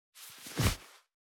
404,パーカーの音,衣服の音,衣類の音,サラッ,シャッ,スルッ,カシャッ,シュルシュル,パサッ,バサッ,
効果音洋服関係